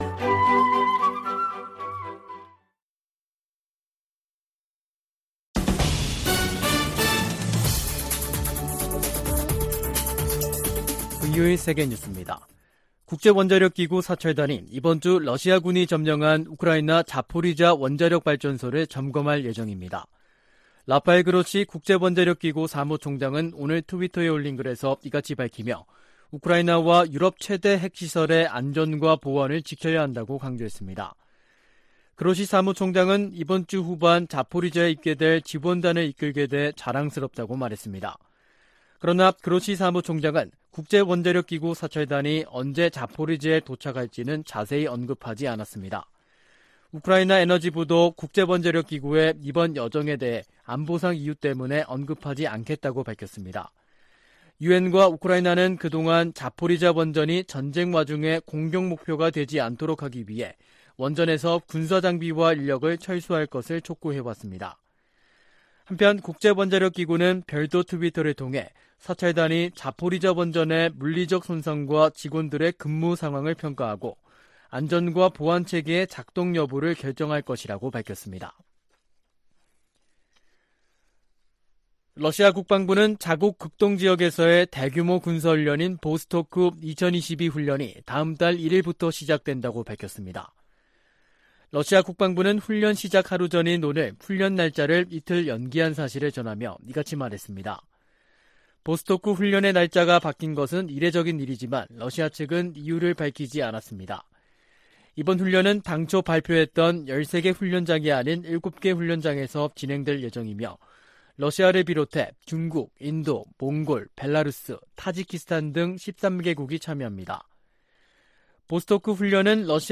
VOA 한국어 간판 뉴스 프로그램 '뉴스 투데이', 2022년 8월 29일 2부 방송입니다. 북한이 핵실험 준비를 마치고 한국을 향해 보복성 대응을 언급하고 있다고 한국 국방부 장관이 말했습니다. 제10차 핵확산금지조약 (NPT) 평가회의가 러시아의 반대로 최종 선언문을 채택하지 못한 채 끝났습니다. 호주가 주관하는 피치블랙에 처음 참가하는 일본은 역내 연합훈련이 다각적이고 다층적인 안보 협력 추진으로 이어진다고 밝혔습니다.